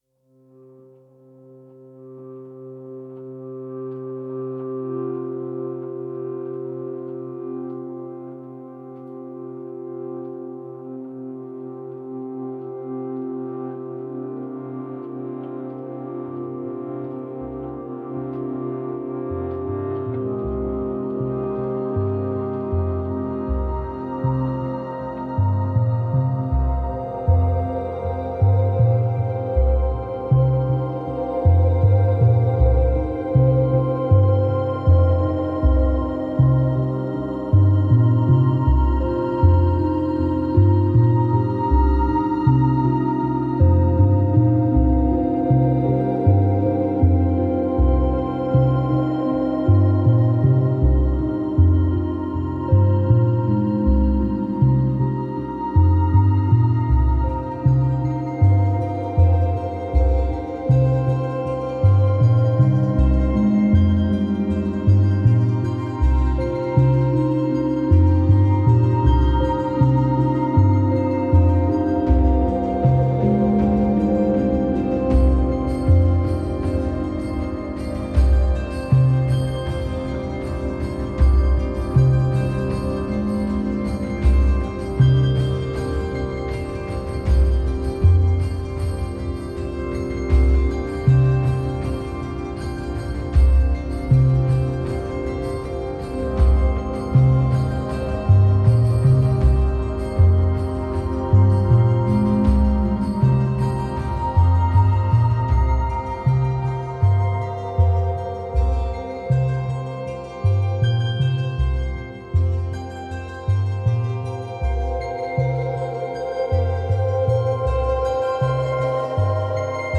A melodious softening of an atmospheric pulse.